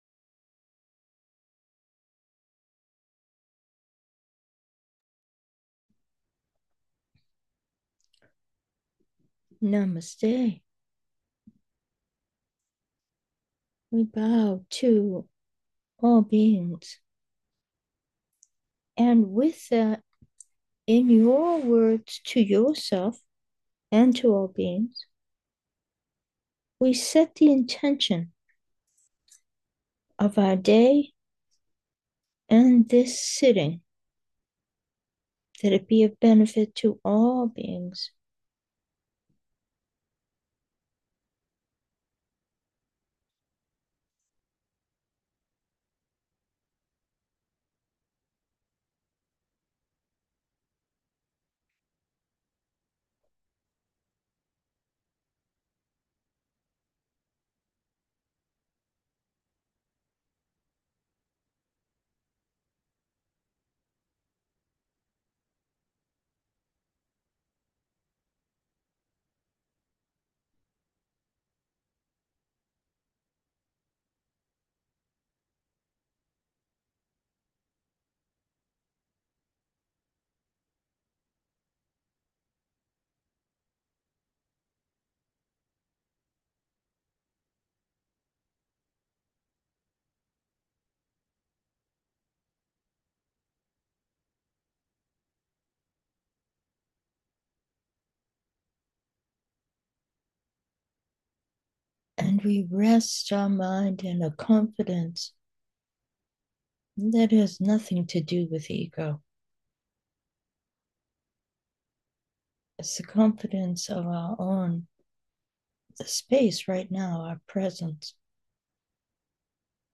Meditation: with intention 2